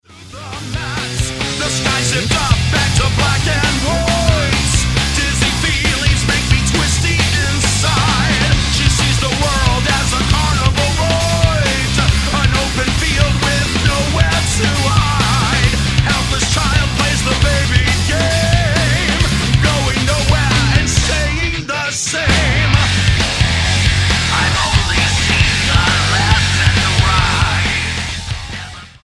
Rock Album
Style: Hard Music